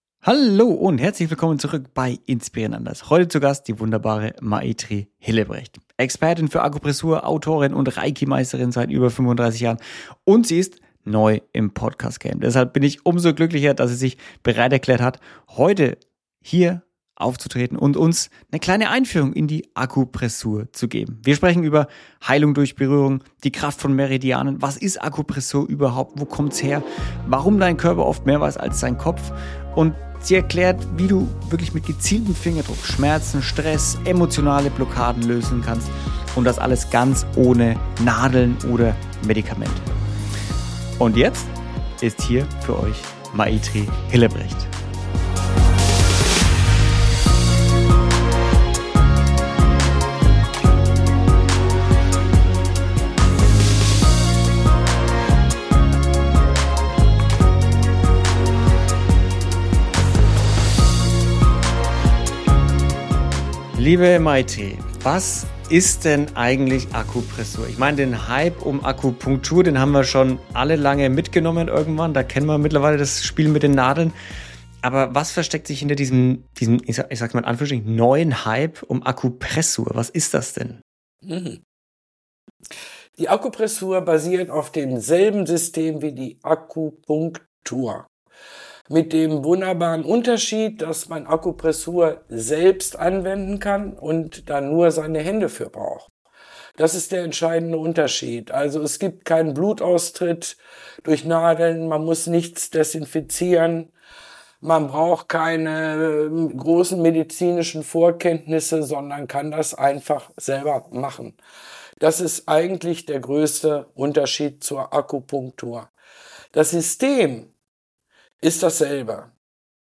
In diesem Gespräch erfährst du, warum Akupressur nicht nur bei körperlichen Schmerzen hel...